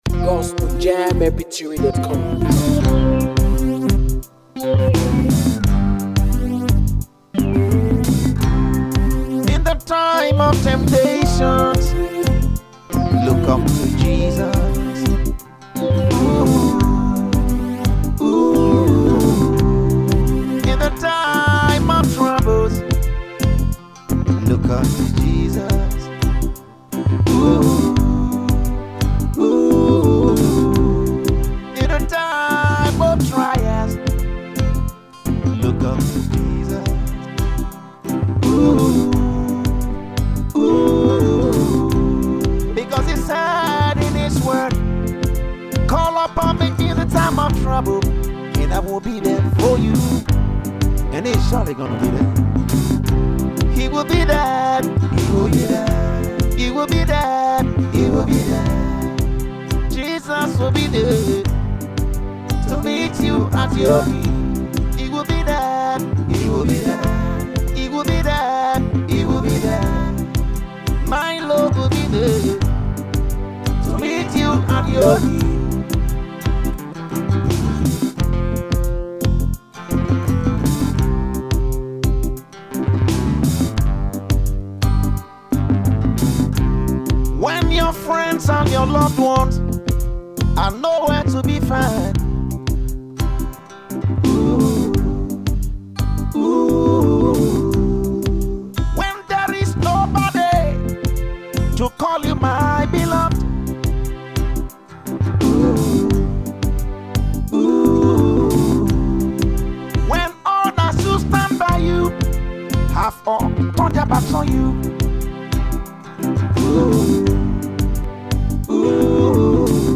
a faith-lifting gospel song